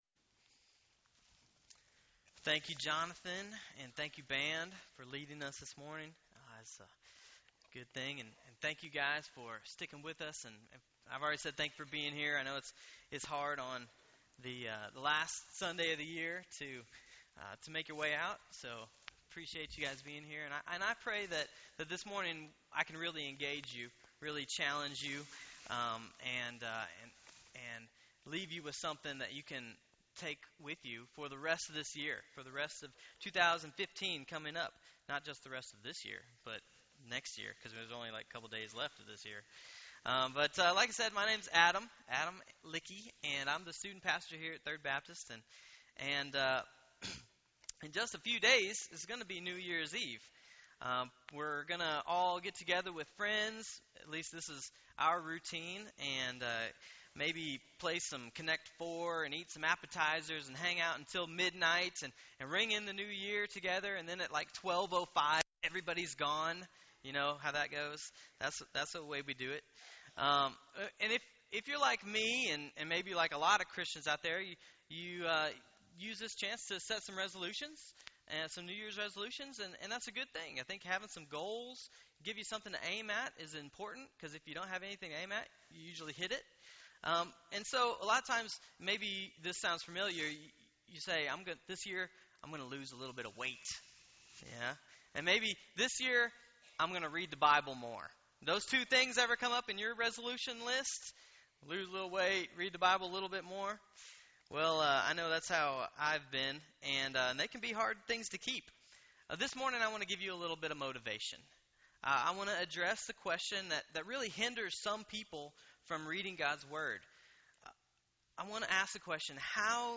Yesterday I had the privilege to preach at our church on this very important question.